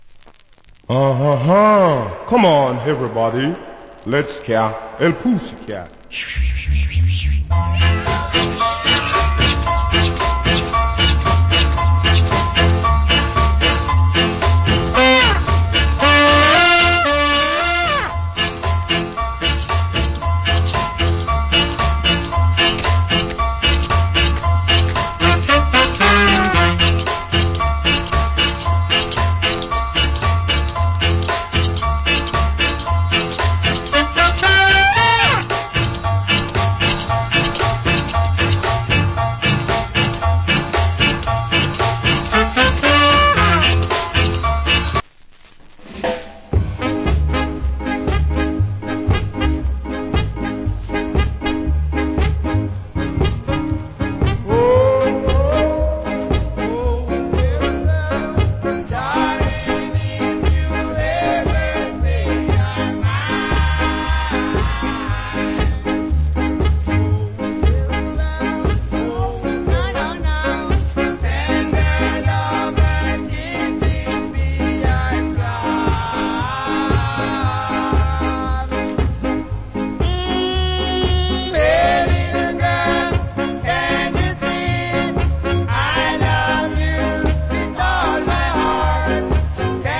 category Ska
Condition VG+(PRESS NOISE,WOL) /EX
Wicked Ska Inst. / Nice Ska Vocal.